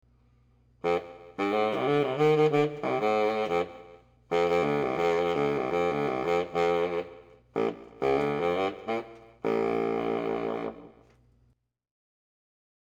basssax.mp3